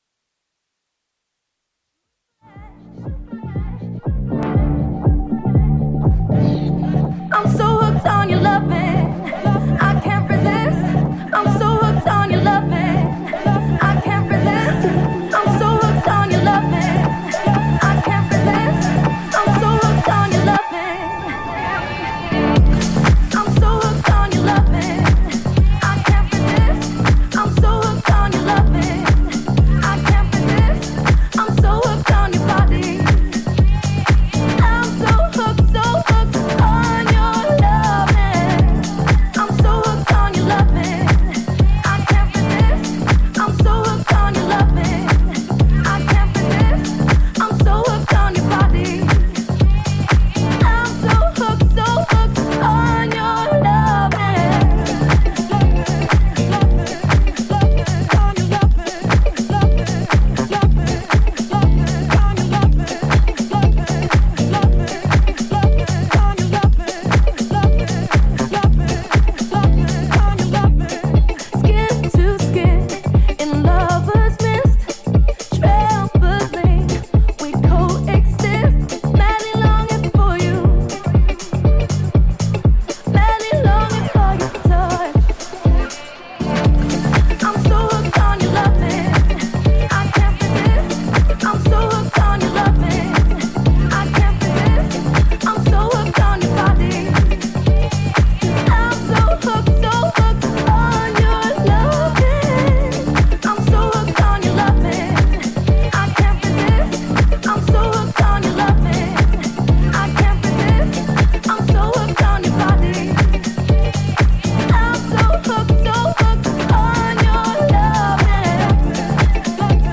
ジャンル(スタイル) HOUSE / SOULFUL HOUSE